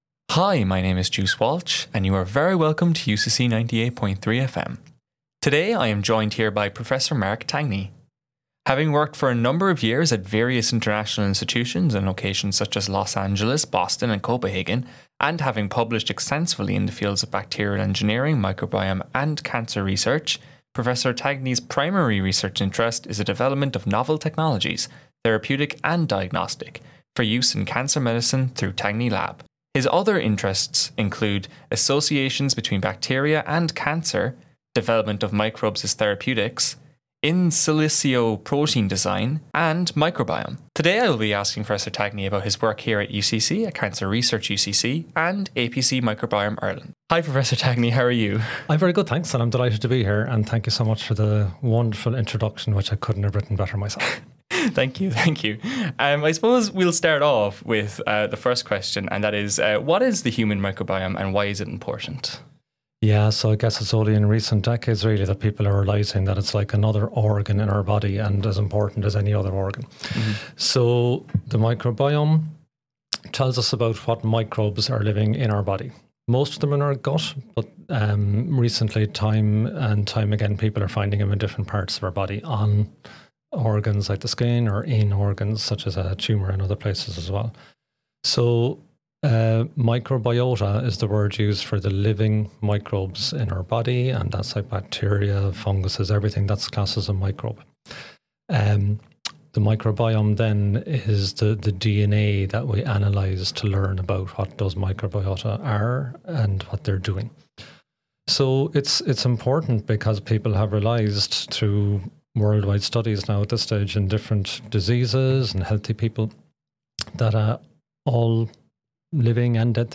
on UCC FM